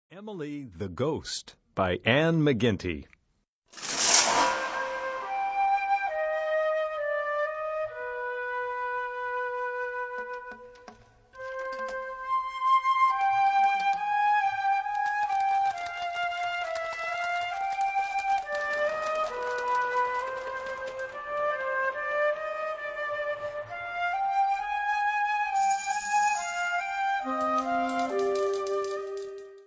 Kategorie Blasorchester/HaFaBra
Unterkategorie Konzertmusik
Besetzung Ha (Blasorchester)
Schwierigkeitsgrad 1